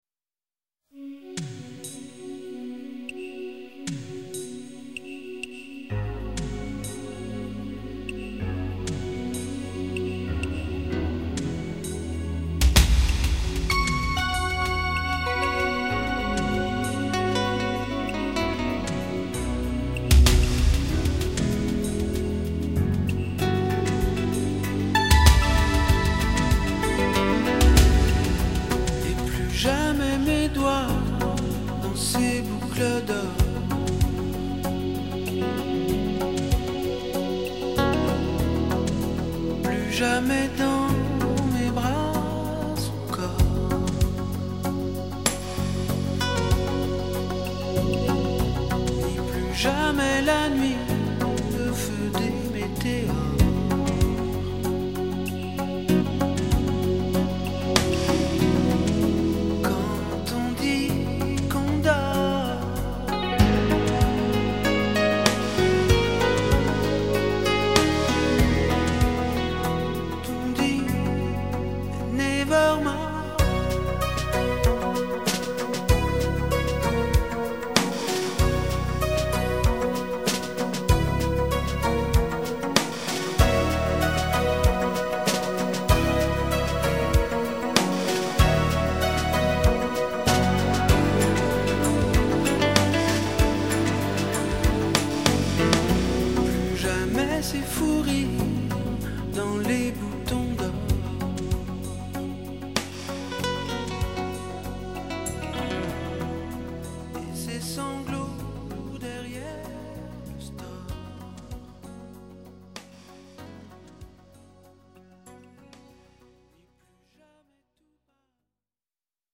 tonalité SI majeur